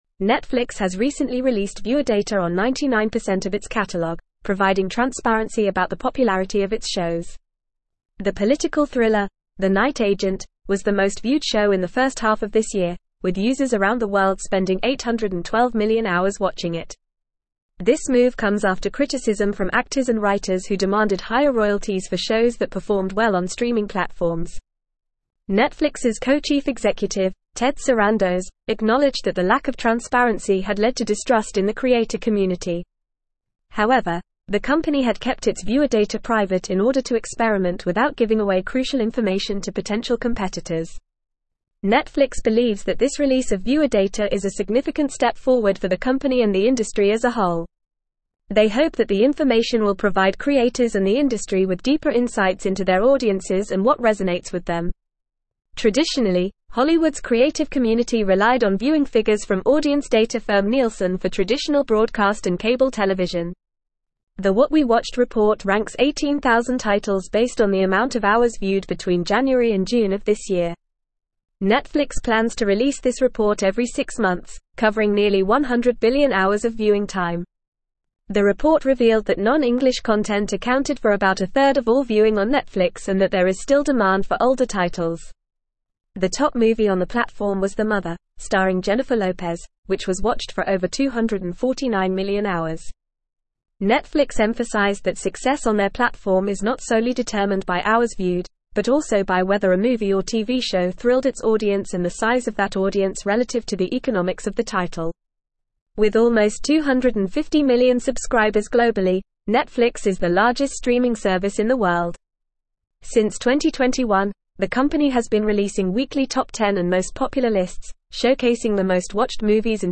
Fast
English-Newsroom-Advanced-FAST-Reading-Netflix-Reveals-Most-Watched-Show-and-Movie-of-2022.mp3